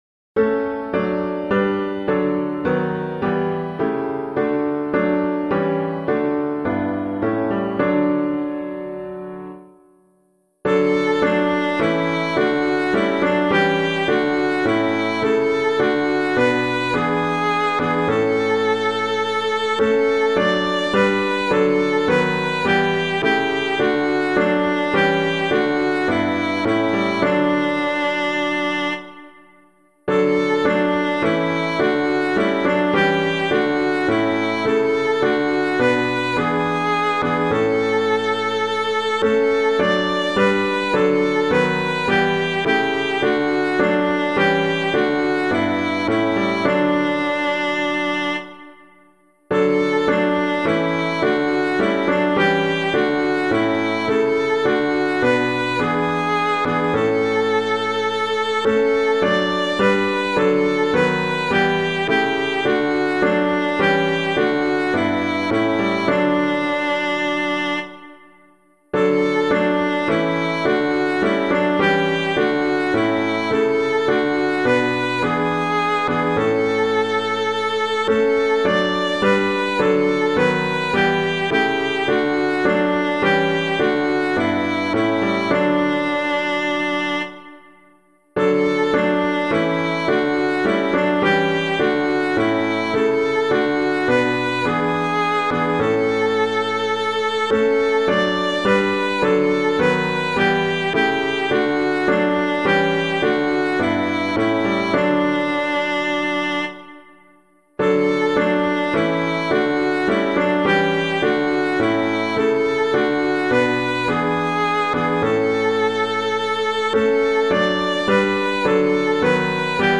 All Ye Who Seek a Comfort Sure [Caswall - SAINT BERNARD] - piano.mp3